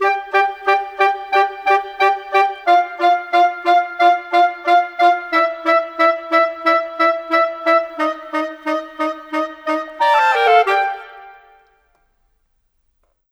Rock-Pop 07 Winds 01.wav